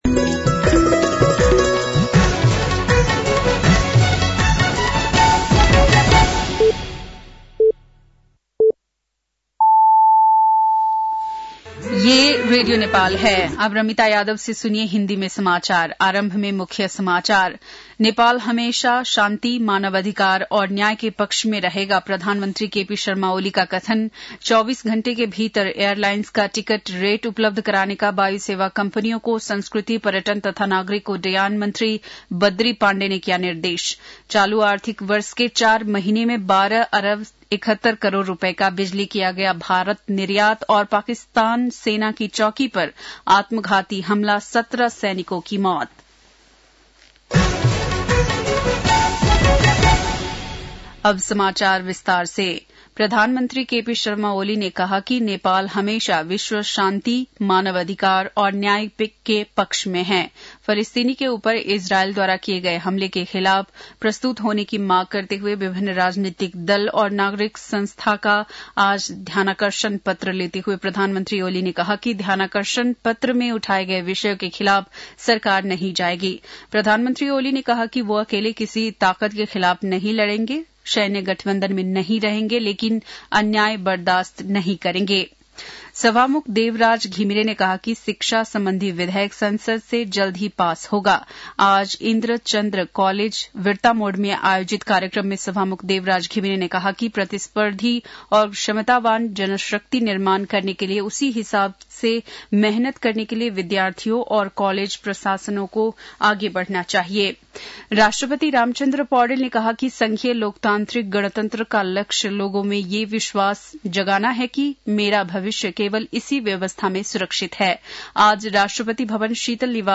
बेलुकी १० बजेको हिन्दी समाचार : ६ मंसिर , २०८१